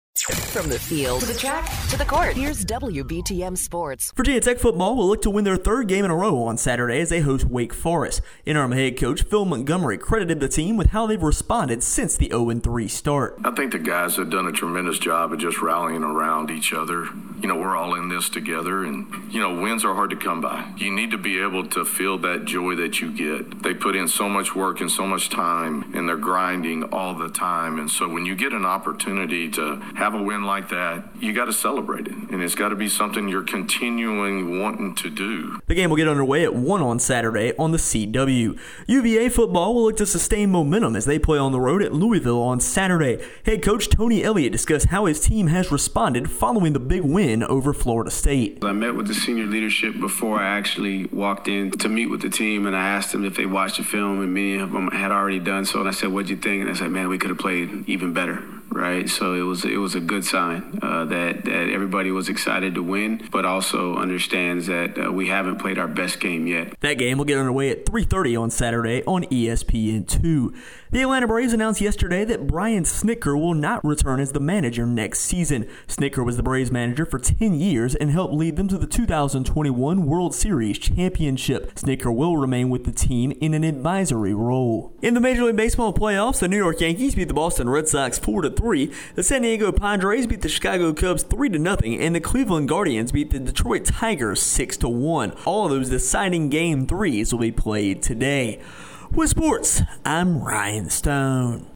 Hokies Preparing for Wake Forest, UVA Trying to Avoid Letdown and More in Our Local Sports Report